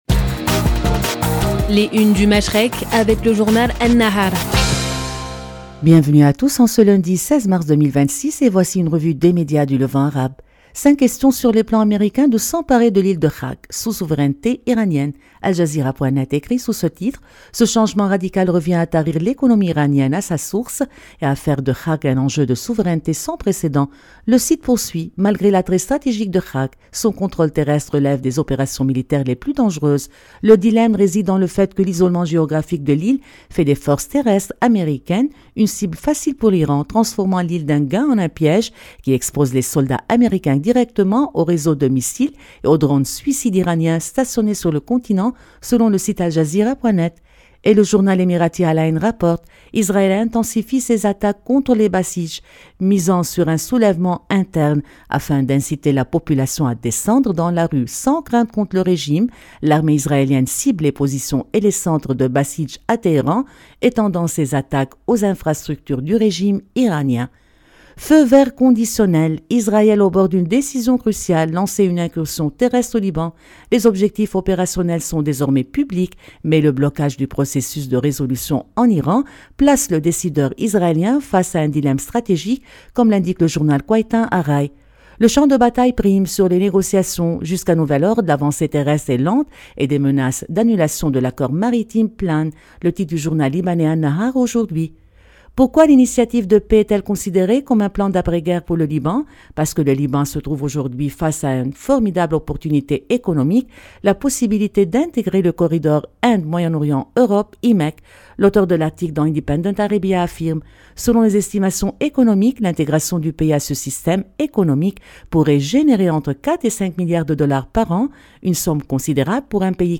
Revue de presse des médias du Moyen-Orient